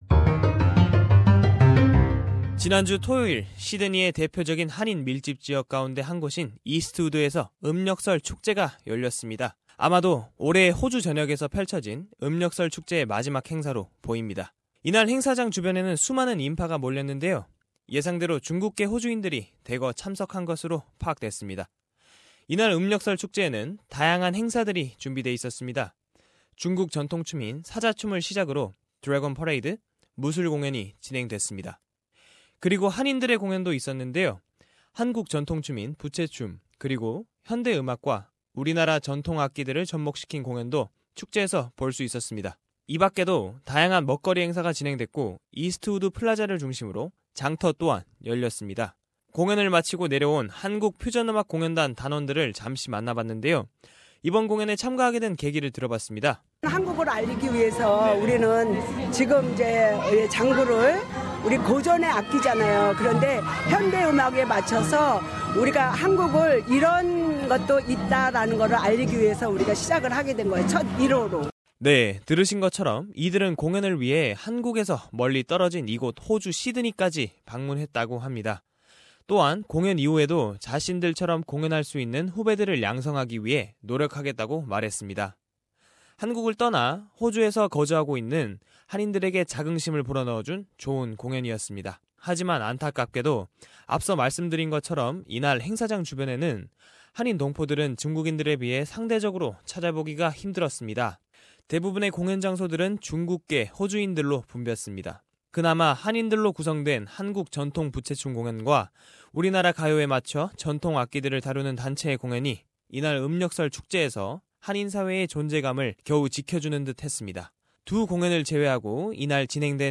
한국 퓨전 음악 공연단과 이날 축제장을 찾은 관객들과 함께 음력설 축제의 이모저모에 대해 의견을 나눴습니다. [자세한 내용은 오디오 뉴스를 통해 접하시기 바랍니다.]